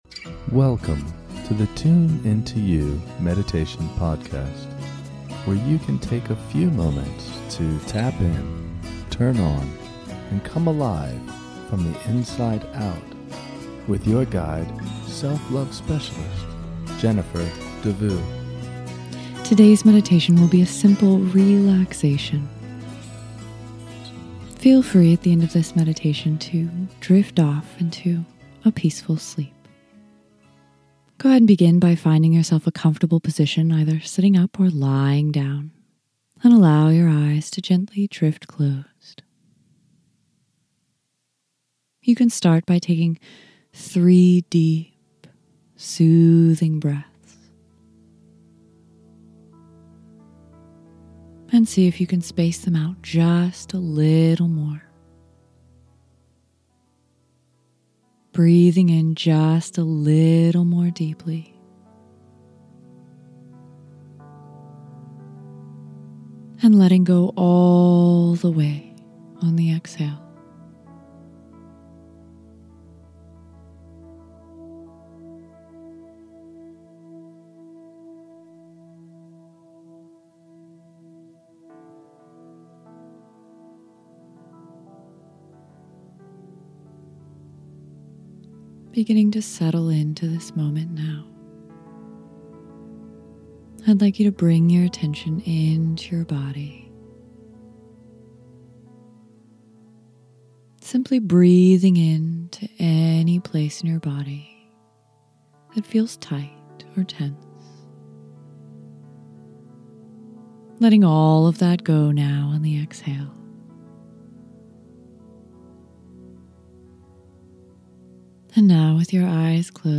This is a short guided meditation to help you drift off into a peaceful and restful sleep. Listen to this meditation whenever you would like to revisit the calming and relaxing experience of drifting off to sleep on a blanket in the park.